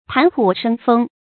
談吐生風 注音： ㄊㄢˊ ㄊㄨˇ ㄕㄥ ㄈㄥ 讀音讀法： 意思解釋： 談話機敏風趣。